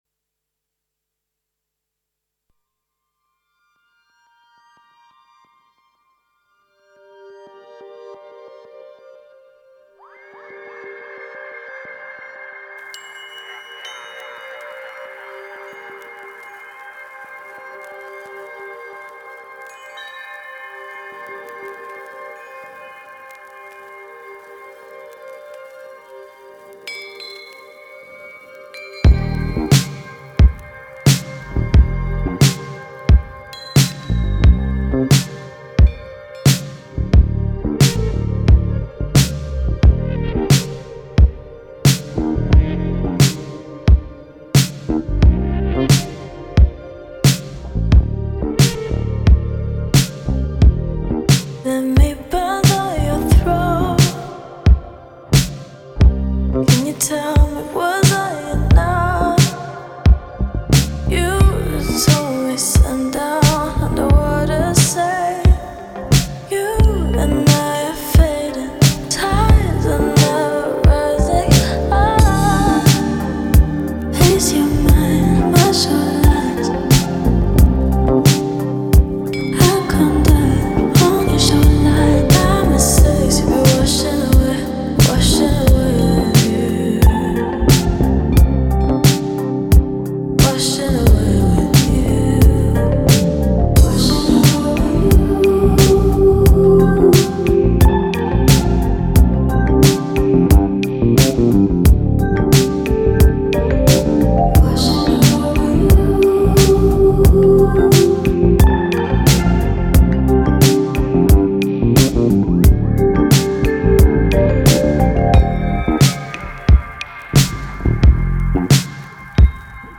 A selection of mixes and radio shows